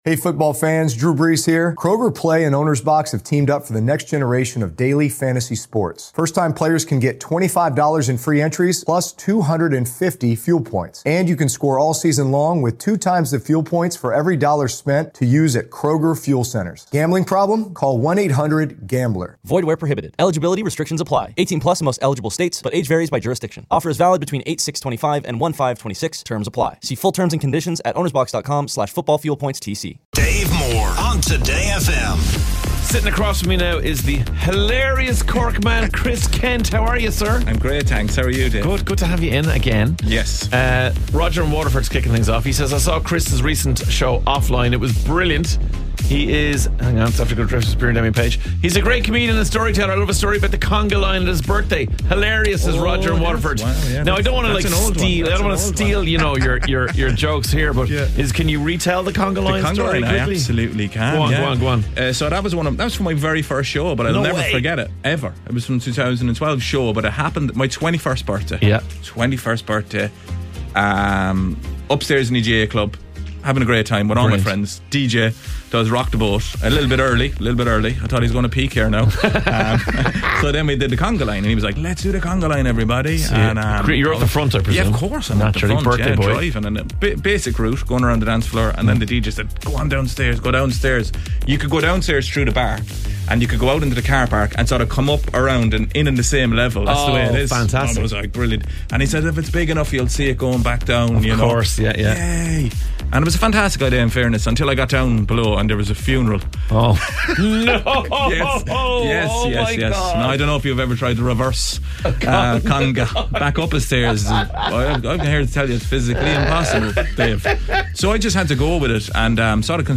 expect laughs, chats and some very Bad Jokes